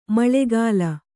♪ maḷegāla